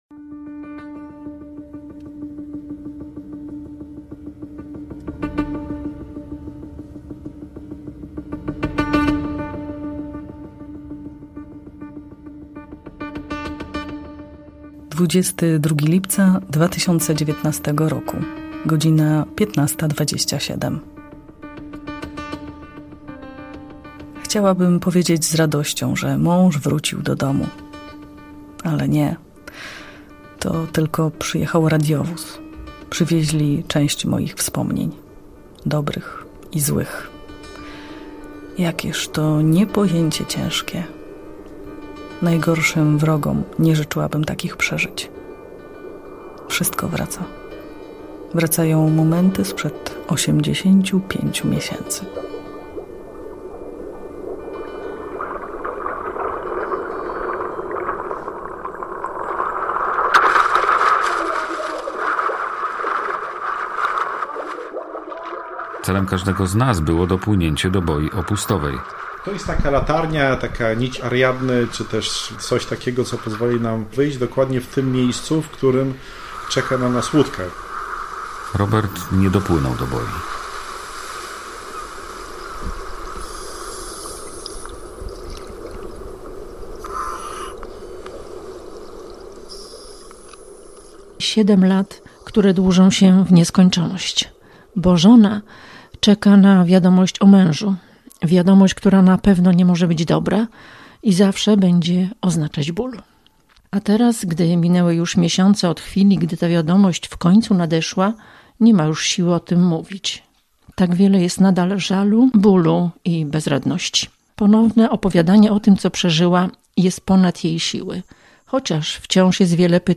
Zwycięski reportaż
19_09_Reportaz_Nie_do_wiary.mp3